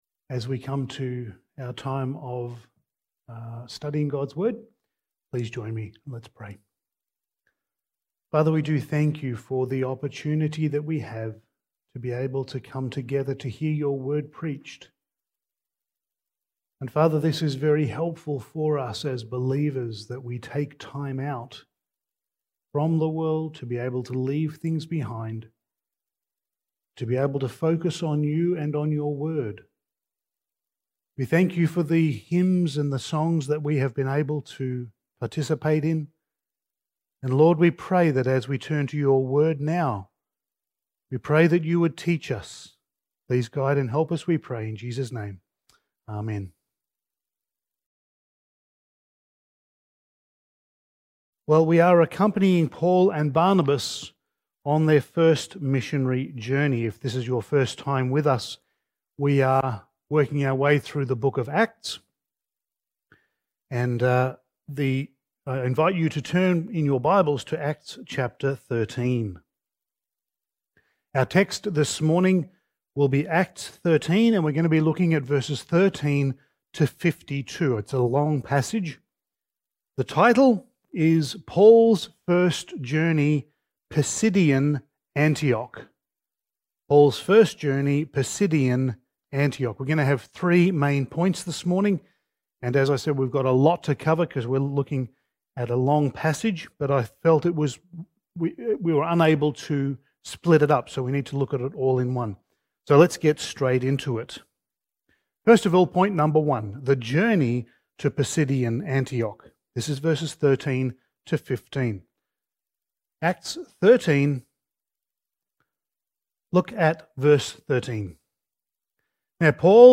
Passage: Acts 13:13-52 Service Type: Sunday Morning